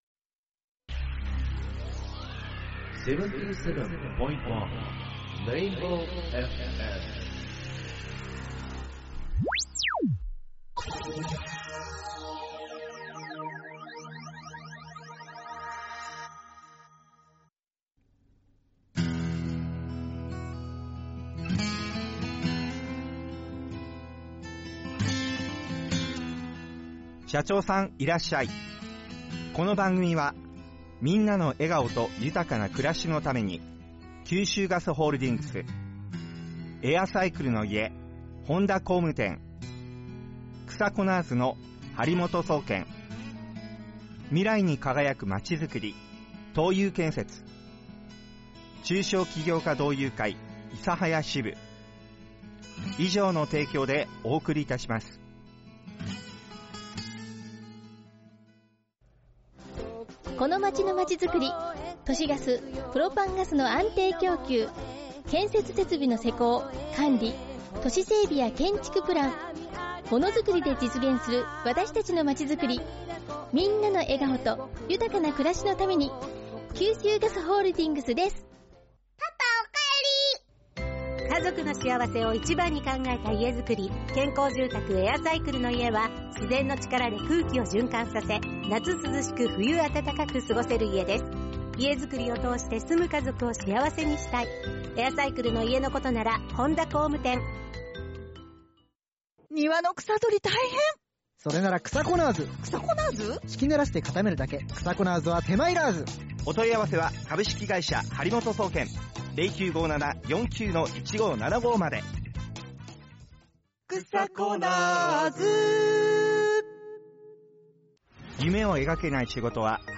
生放送を聴き逃した方はこちらより！